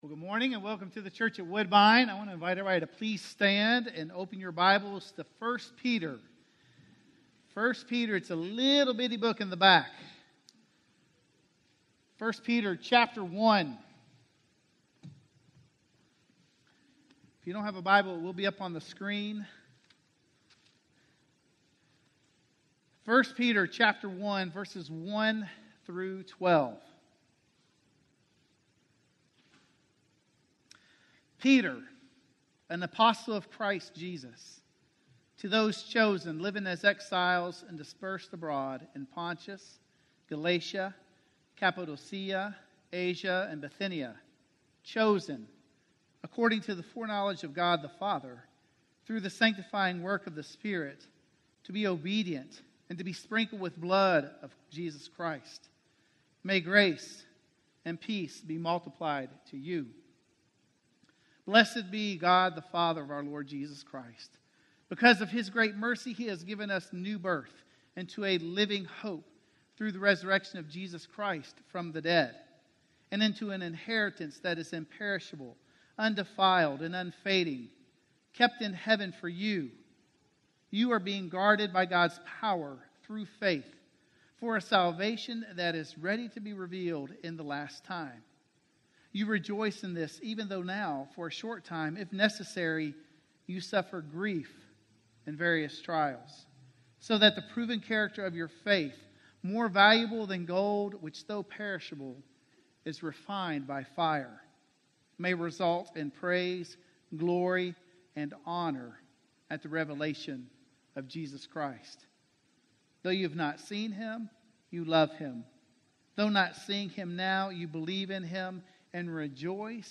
A Living Hope - Sermon - Woodbine